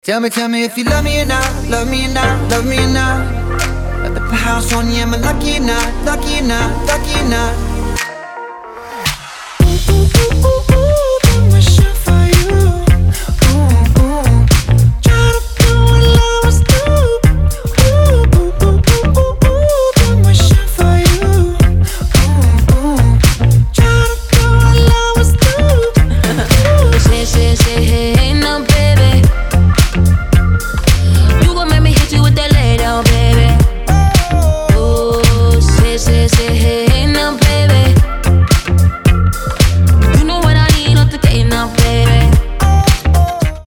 • Качество: 320, Stereo
поп
позитивные
мужской вокал
красивые
dance
Фрагмент бодрой песни про любовь